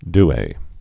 (dā, d-ā)